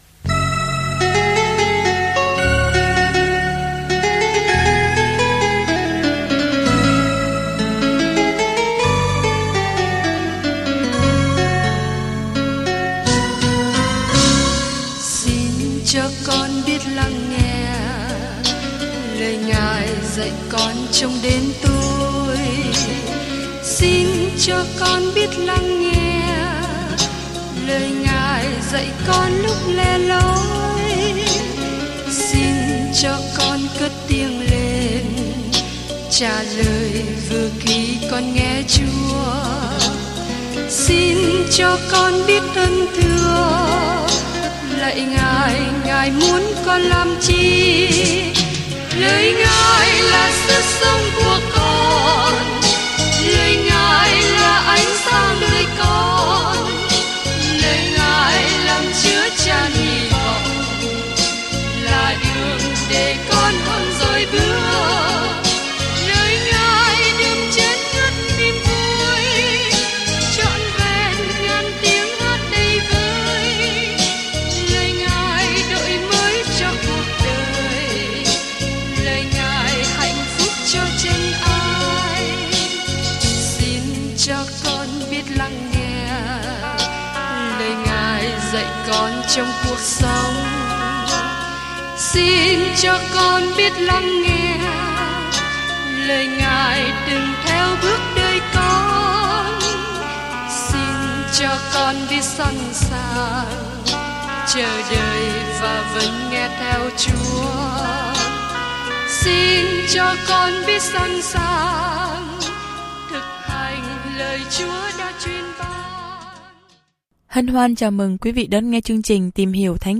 There is an audio attachment for this devotional.